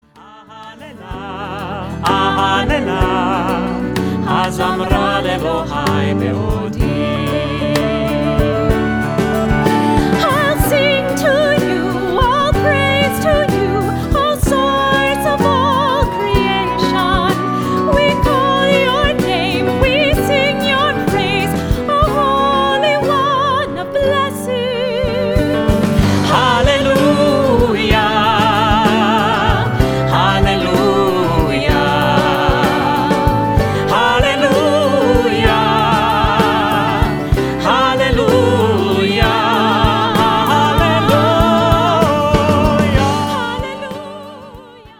a choir and instruments
organist